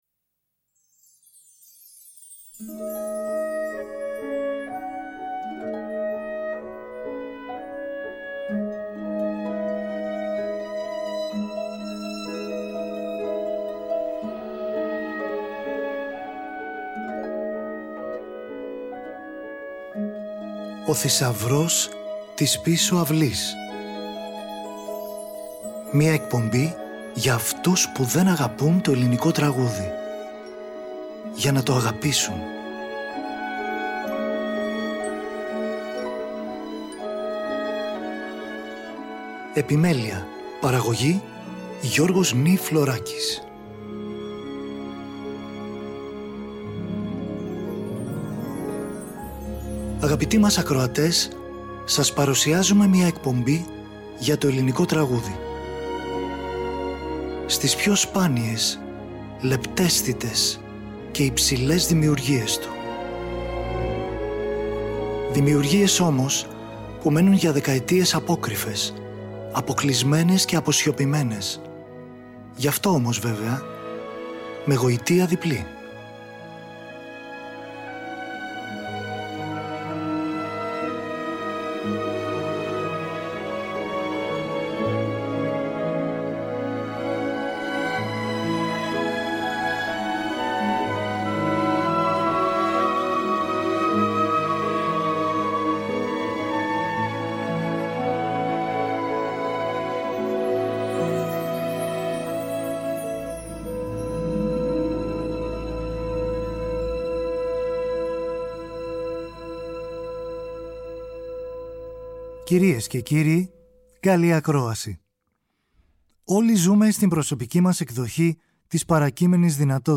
Ελληνικη Μουσικη στο Τριτο